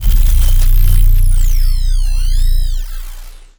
sci-fi_electric_pulse_hum_04.wav